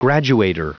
Prononciation du mot graduator en anglais (fichier audio)
Prononciation du mot : graduator